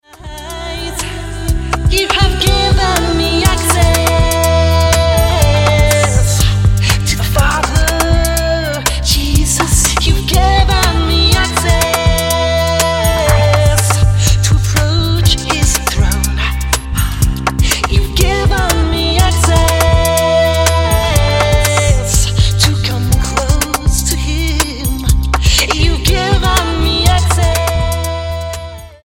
STYLE: Pop
Haunting, spare, magnificent.
Tremendous vocals and playing throughout